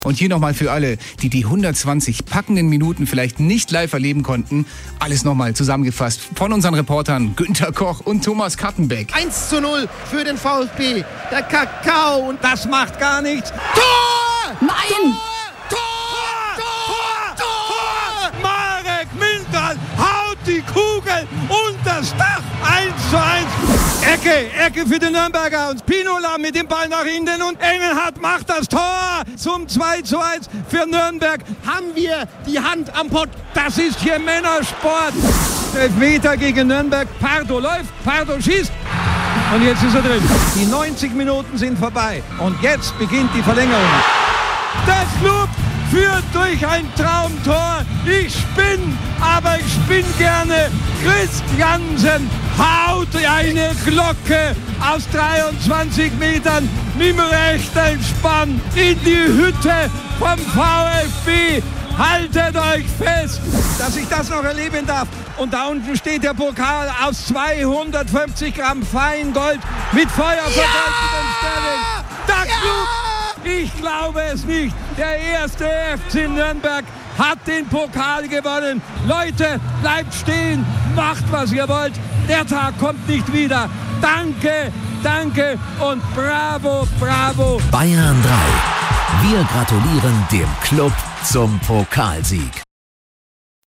Bevor wir loslegten, erzählte er, wie er zum Zeitlieder-Fan wurde: Da er technisch so unbeholfen sei, dass er in seinem Auto nicht mal CDs wechseln kann und dazu auch noch extrem eitel, habe er seit dem Pokalsieg bei jeder Autofahrt die Radioreportage über das Pokalfinale von Günther Koch (einem fränkischen Kultreporter) angehört.